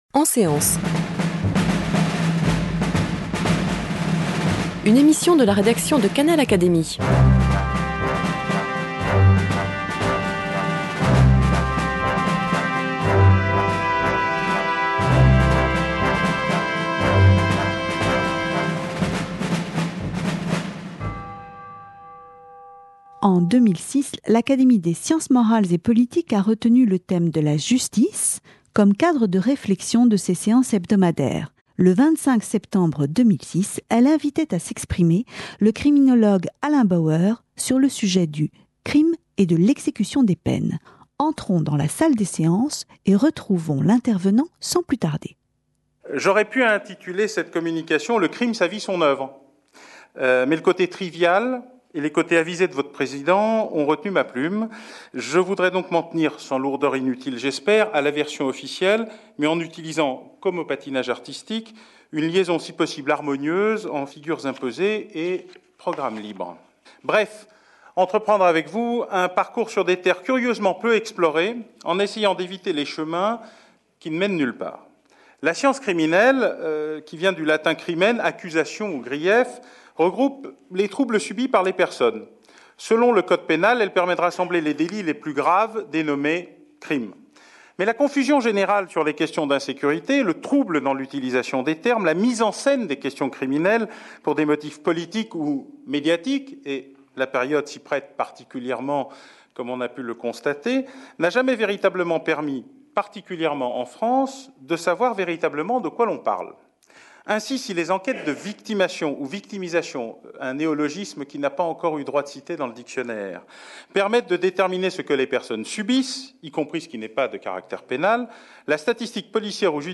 Communication d’Alain Bauer, criminologue et consultant, prononcée devant l’académie des sciences morales et politiques, le 25 septembre 2006.